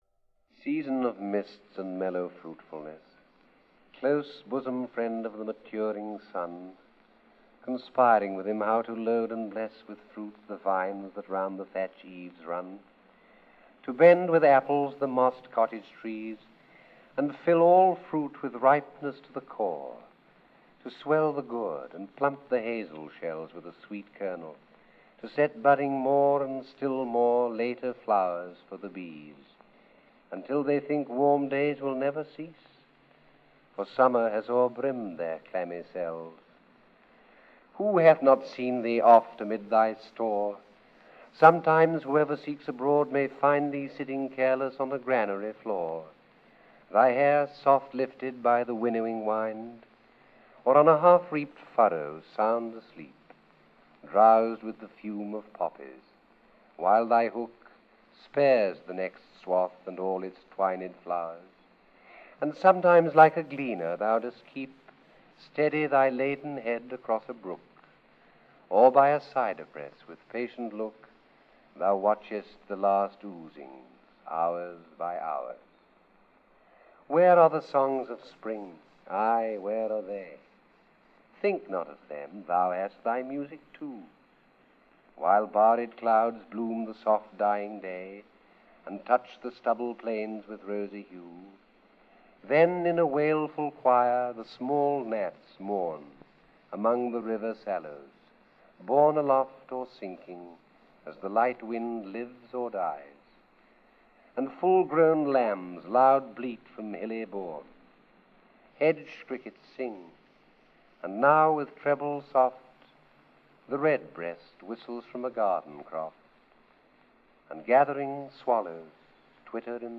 When his health made it difficult for him to pursue acting roles, he began to record favourite poems in his home.